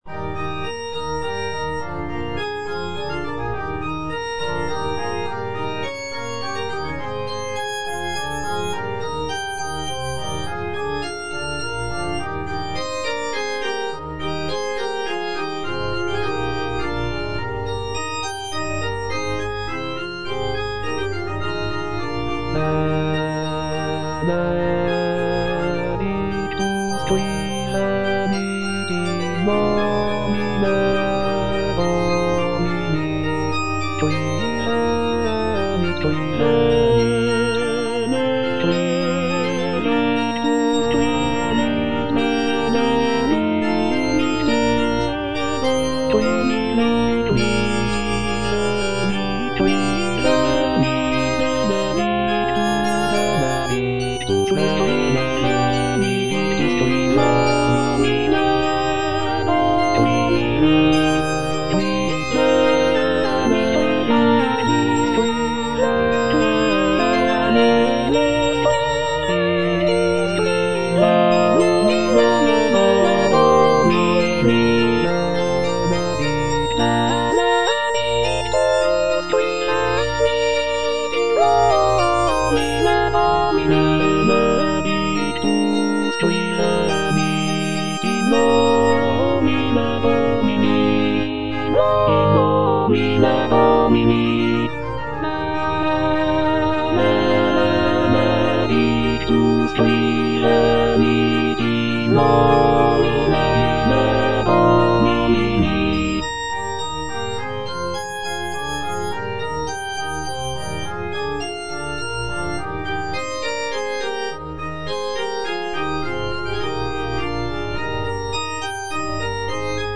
M. HAYDN - REQUIEM IN C (MISSA PRO DEFUNCTO ARCHIEPISCOPO SIGISMUNDO) MH155 Benedictus (All voices) Ads stop: auto-stop